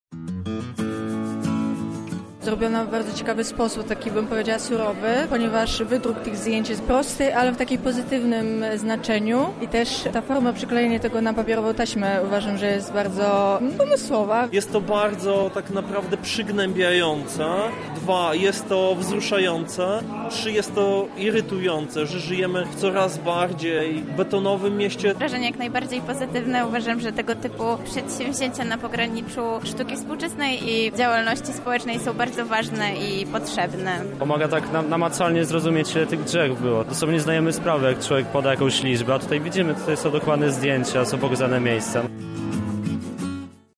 Widzowie podzieli się swoimi opiniami